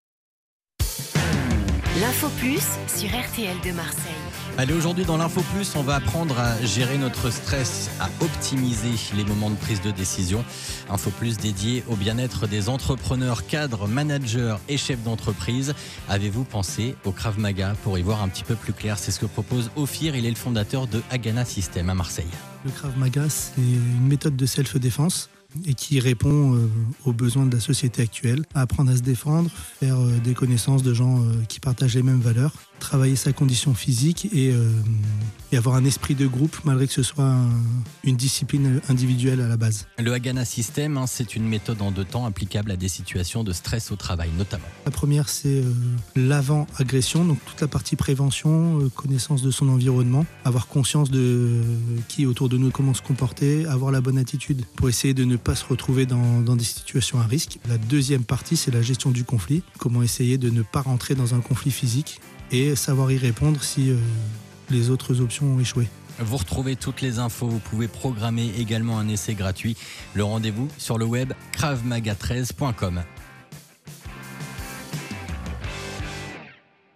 Interviewé par RTL2 - Krav Maga Marseille - Hagana System